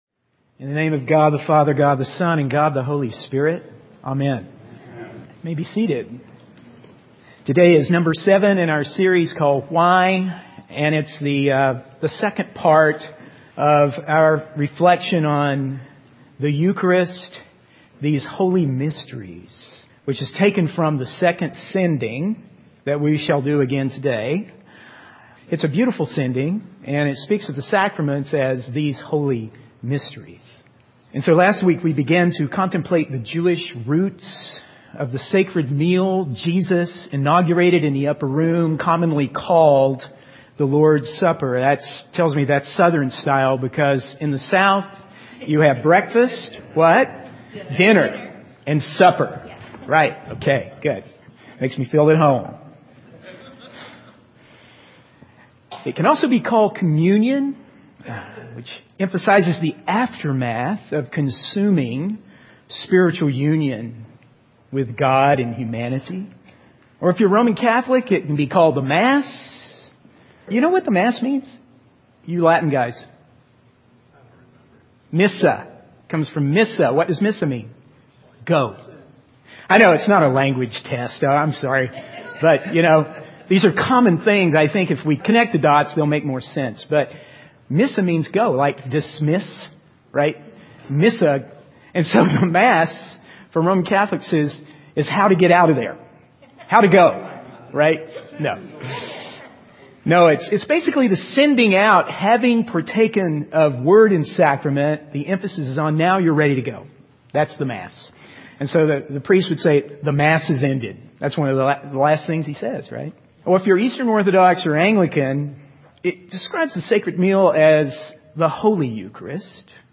In this sermon, the speaker emphasizes the power of confession and the transformative nature of God's word.